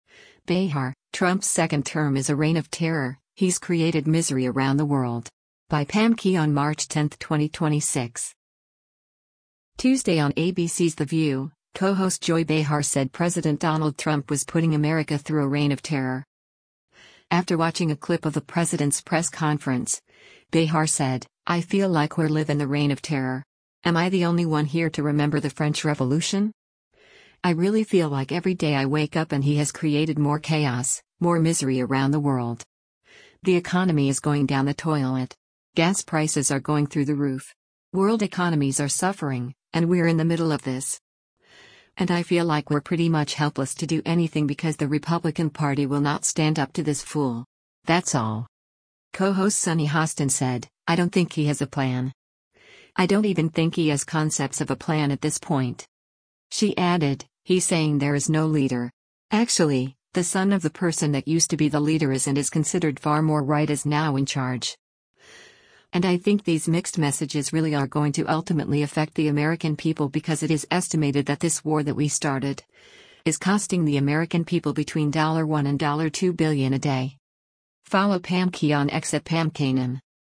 Tuesday on ABC’s “The View,” co-host Joy Behar said President Donald Trump was putting America through a “reign of terror.”